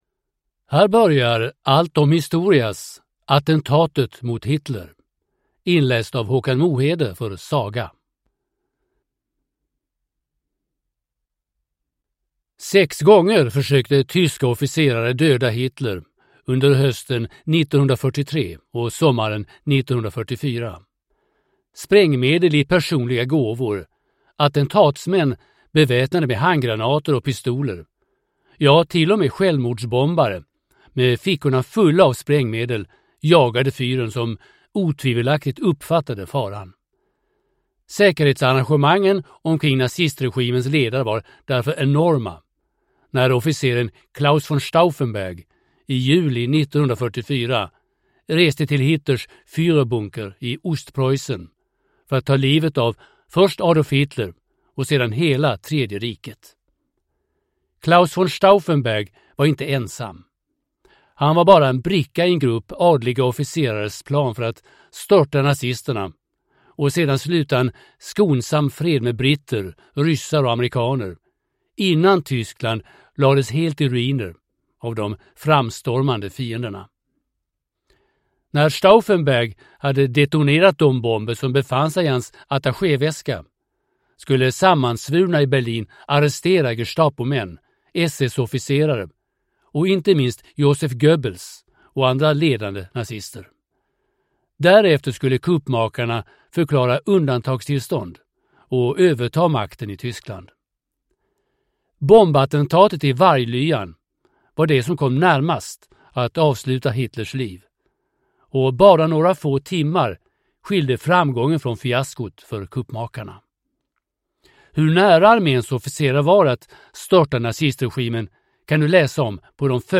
Attentatet mot Hitler (ljudbok) av Allt om Historia